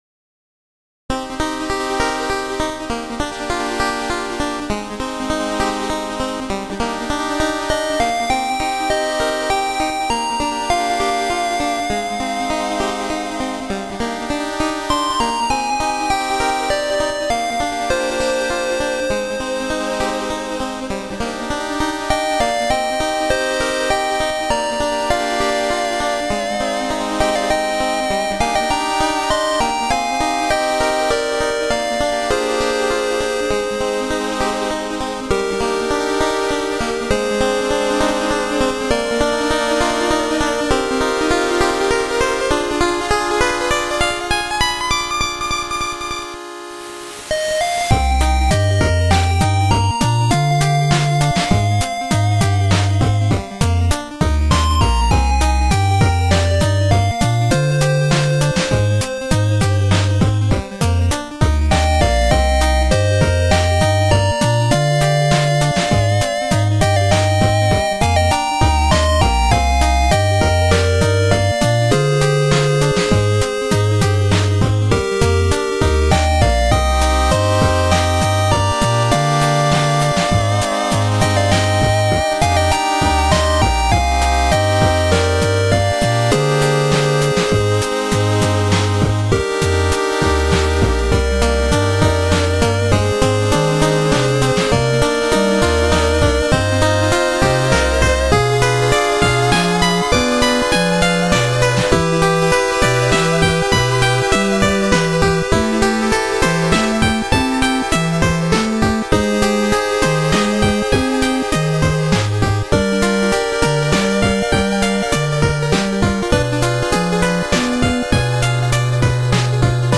8-Bit_Fantasy__Adventure_Music.mp3